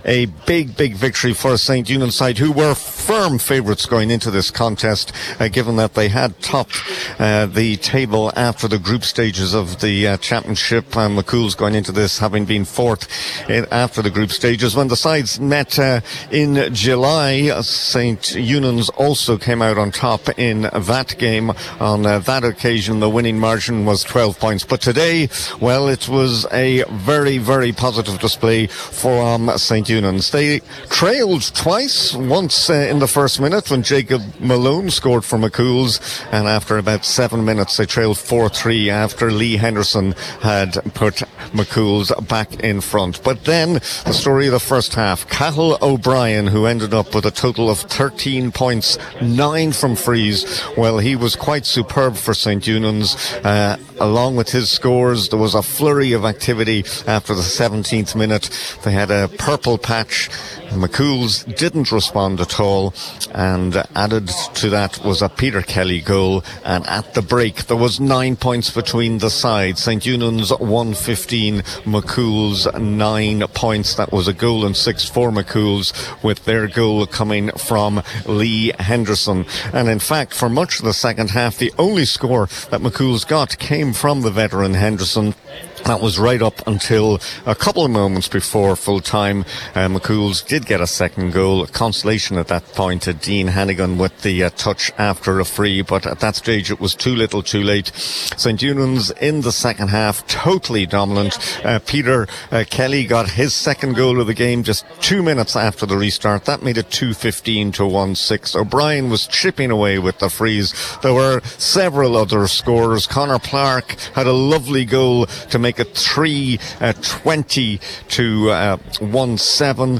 live at full time